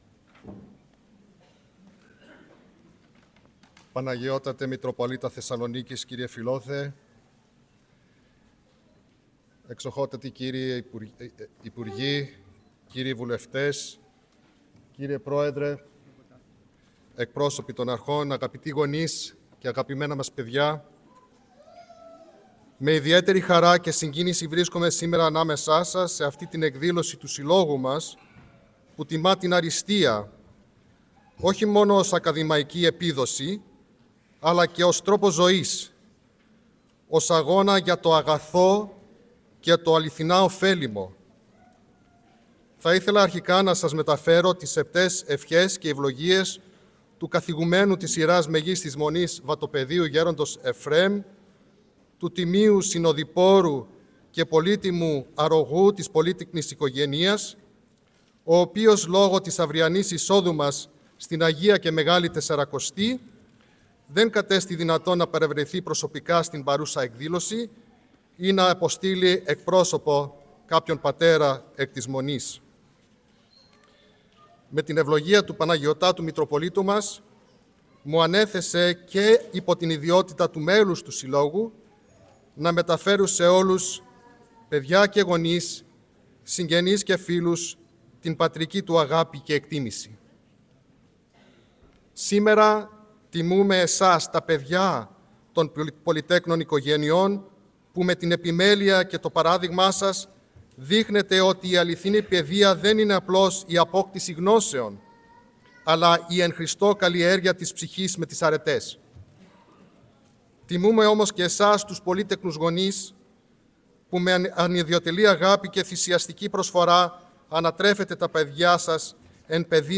Σε μια ιδιαίτερα σημαντική εκδήλωση που πραγματοποιήθηκε σήμερα, Κυριακή 2 Μαρτίου, στην Αίθουσα Τελετών του Αριστοτελείου Πανεπιστημίου Θεσσαλονίκης, ο Κεντρικός Σύλλογος Πολυτέκνων Νομού Θεσσαλονίκης «Άγιοι Πάντες» βράβευσε περίπου 800 αριστούχους μαθητές Γυμνασίου και Λυκείου από πολύτεκνες και υπερπολύτεκνες οικογένειες, αναγνωρίζοντας τις επιδόσεις και την προσπάθειά τους.